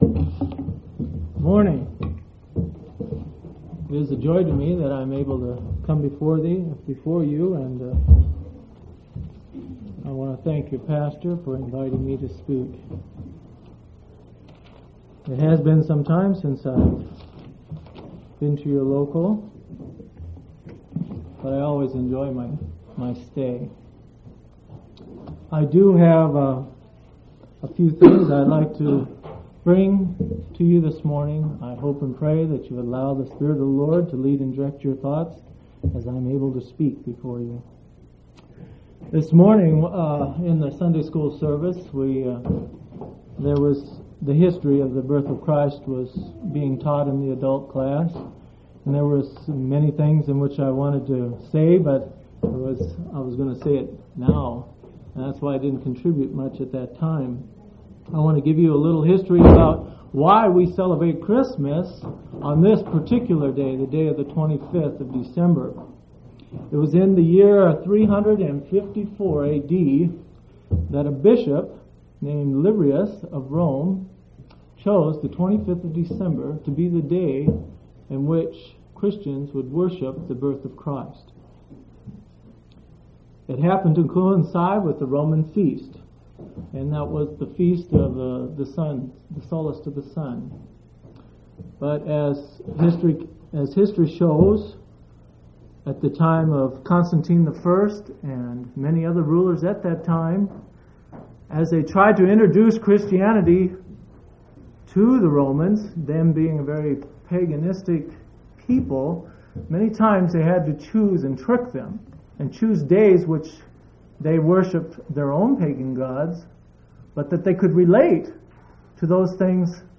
12/25/1983 Location: Grand Junction Local Event